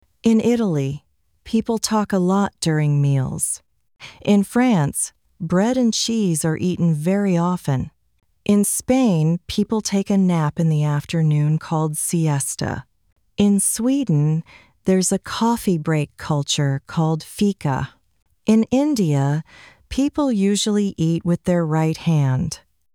アメリカ人